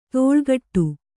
♪ tōḷgaṭṭu